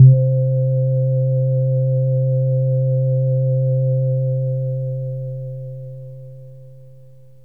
AMBIENT ATMOSPHERES-4 0011.wav